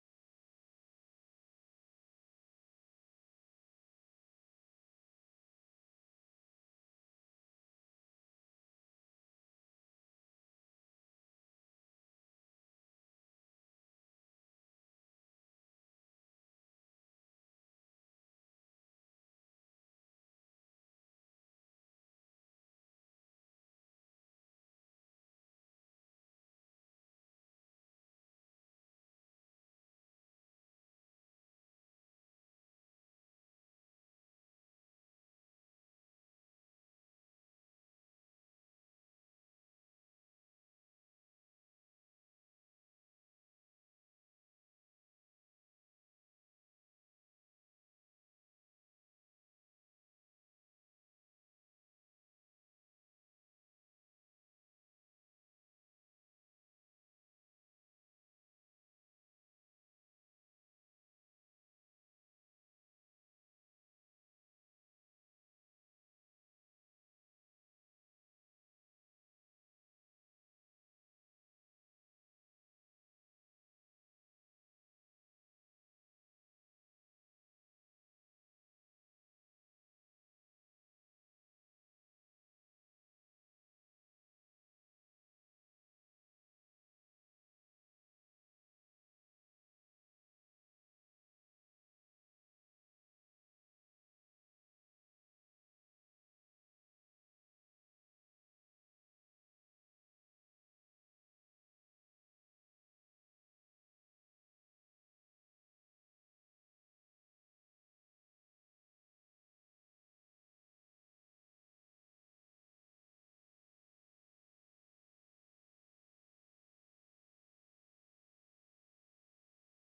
محاضرة بعنوان شهر الله المحرم